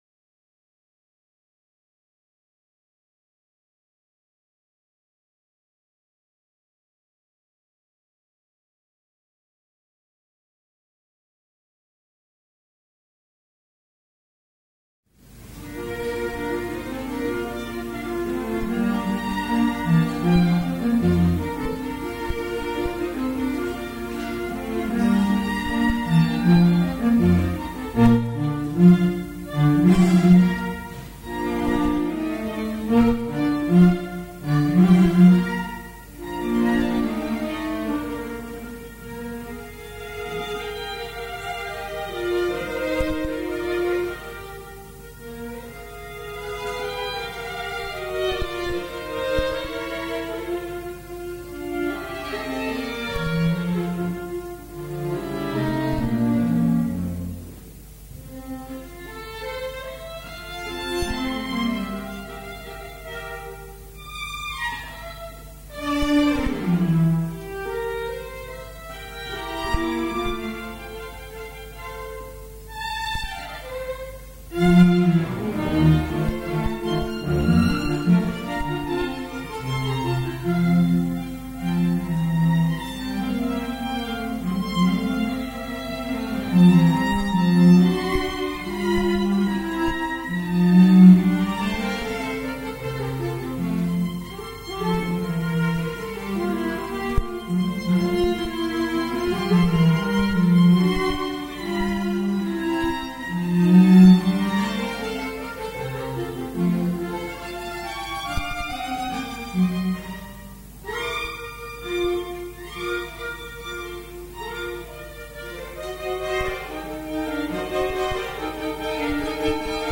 Musikalische Eröffnung